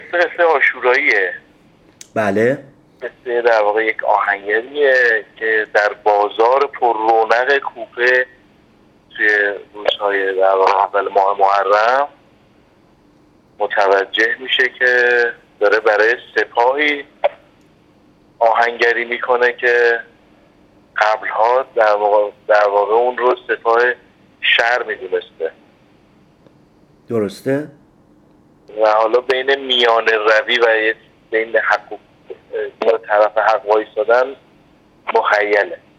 گفت‌گو